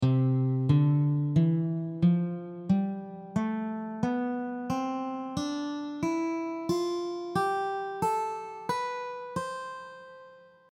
The examples will help you to hear the notes of each scale.
C Major scale
Major-scale-audio.mp3